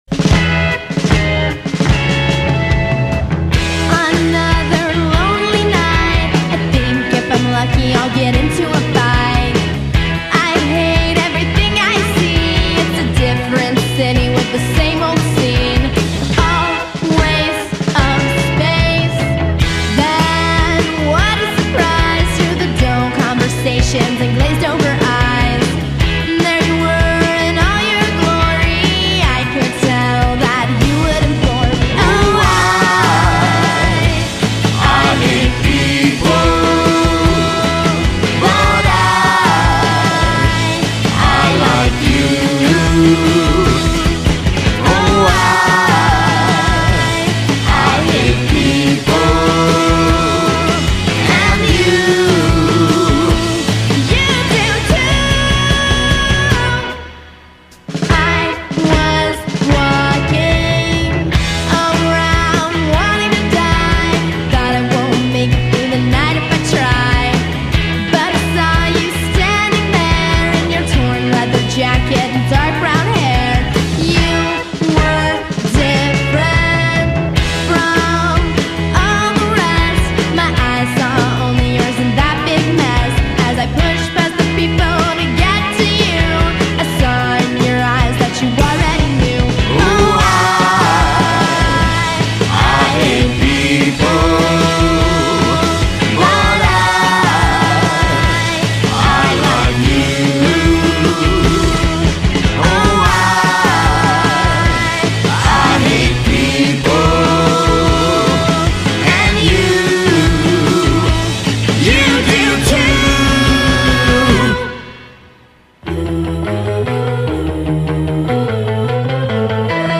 garage-rock heaven